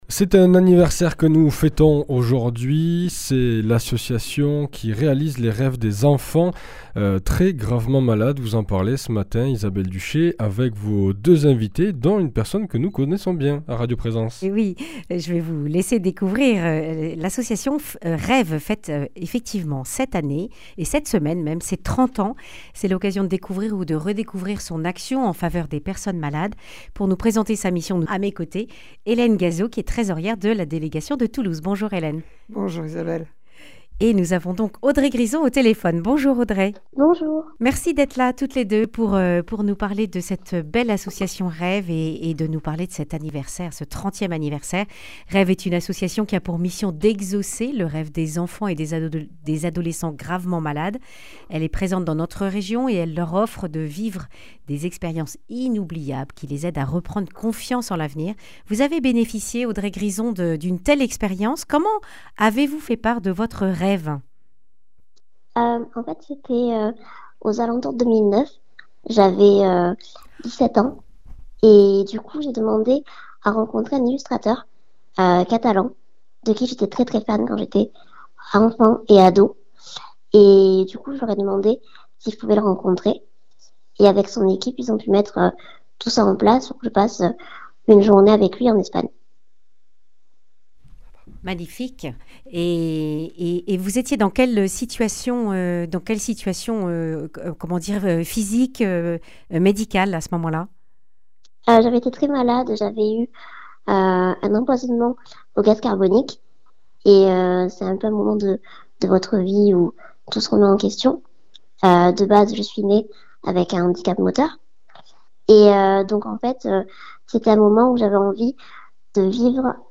Elle en témoigne dans cette interview.